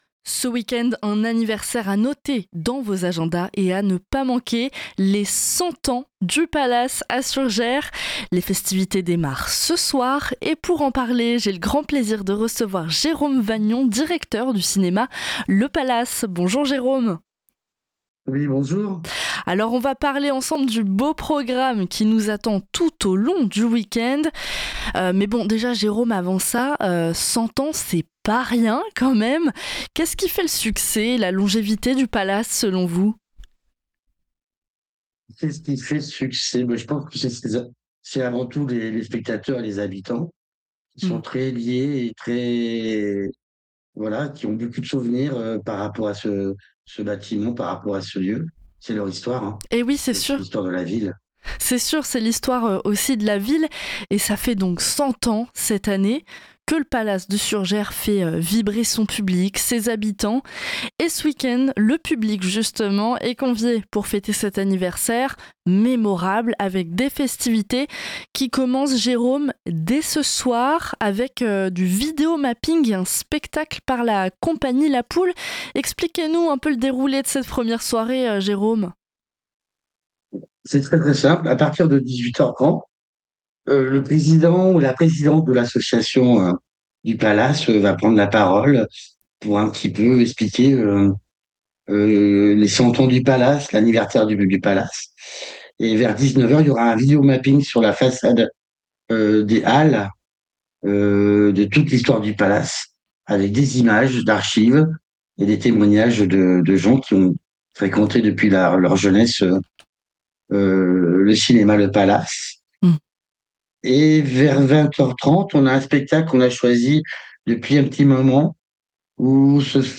L’interview est à retrouver ci-dessous.